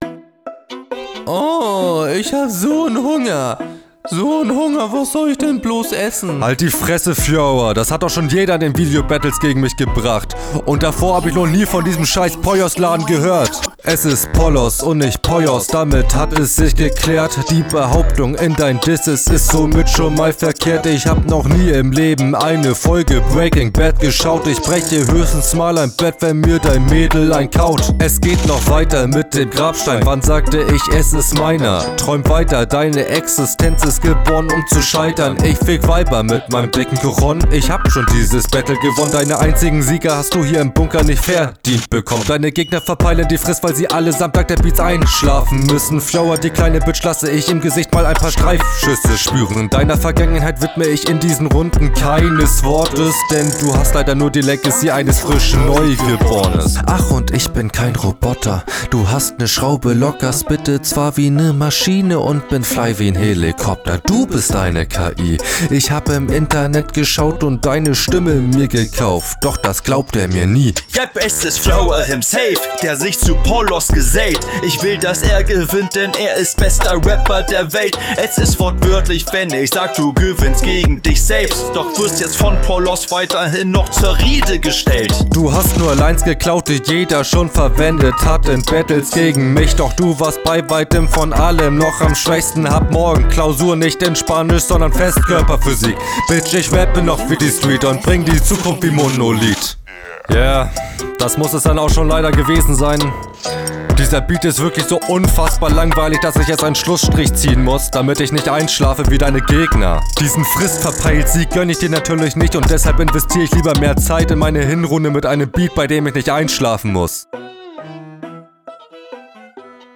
🤔 Das liegt unter anderem am schlechteren Mix.